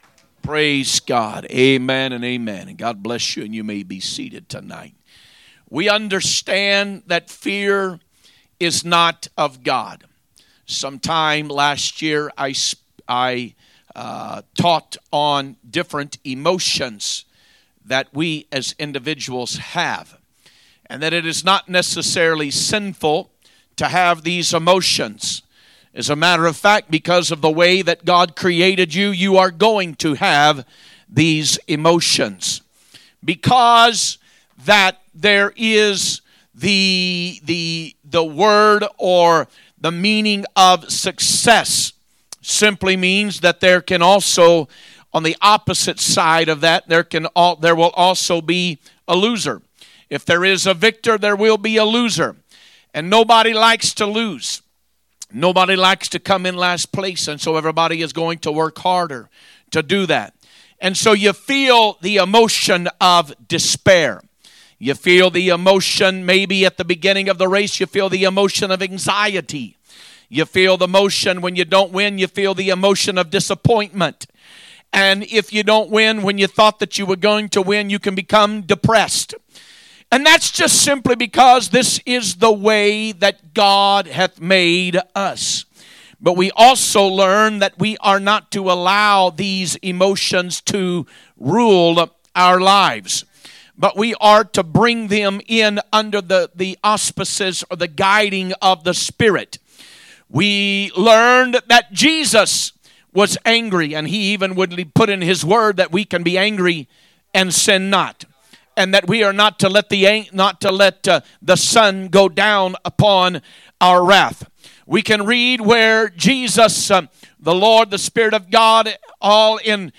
A message from the series "2025 Preaching." 3/12/2025 Wednesday Service
2025 Sermons